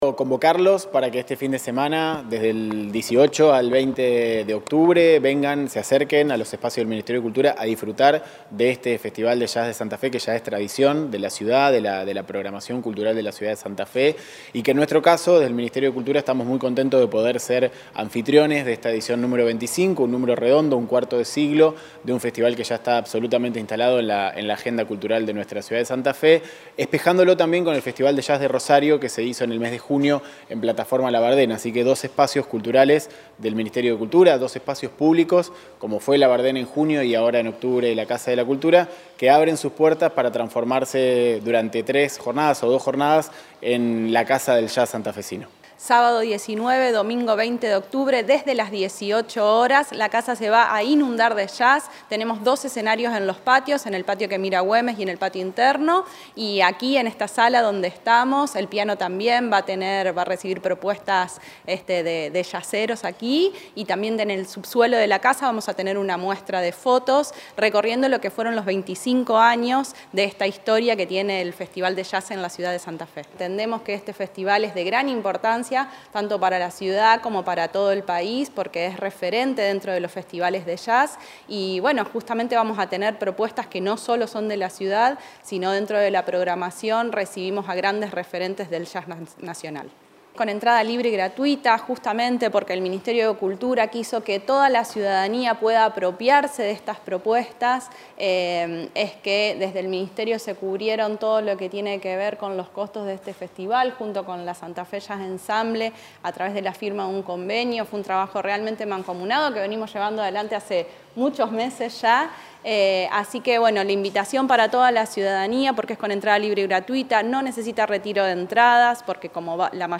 La conferencia de prensa se brindó en ese espacio cultural, bulevar Gálvez 1274.